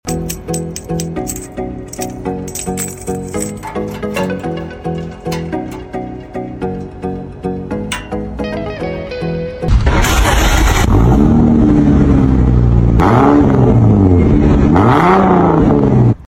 🚗✨ TURBO SOUND WHISTLE MUFFLER sound effects free download
TURBO SOUND WHISTLE MUFFLER EXHAUST